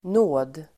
Uttal: [nå:d]